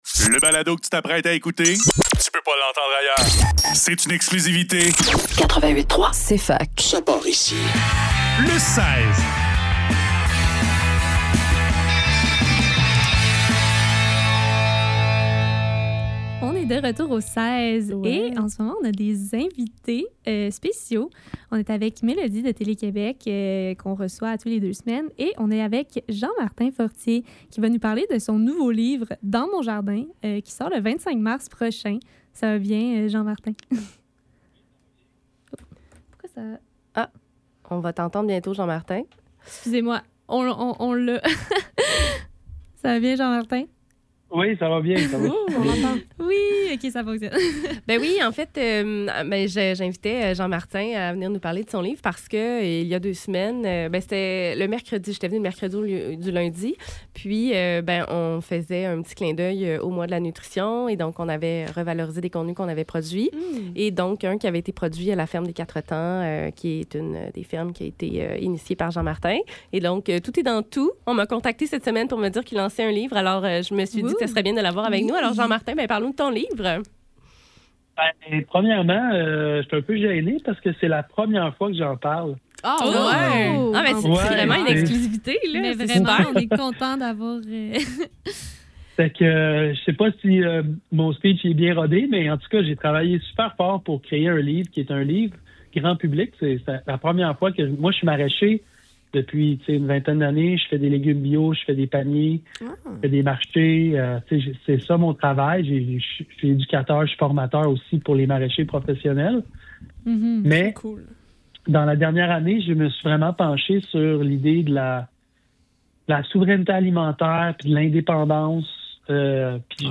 invité téléphone